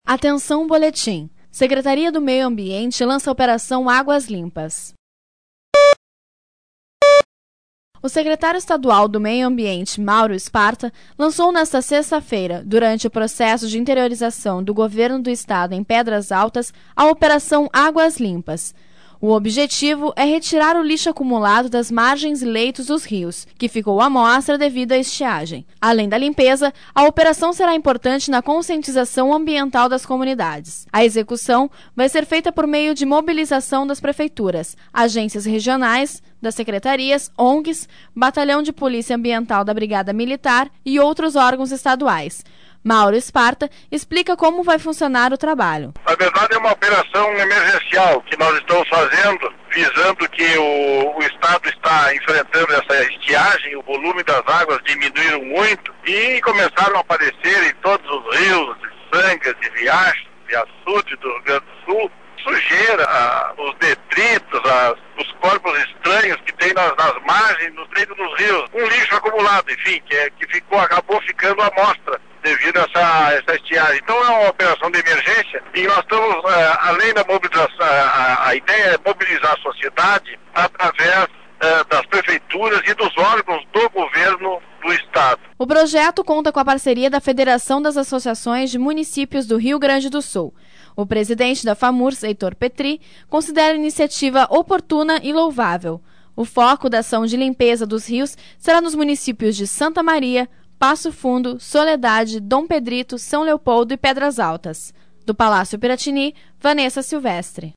O secretário estadual do Meio Ambiente, Mauro Sparta, lançou nesta sexta-feira, durante o processo de interiorização do Governo do Estado, em Pedras Altas a Operação Águas Limpas (sonora: secretário do Meio Ambiente, Mauro Sparta)Local: Porto Alegre -R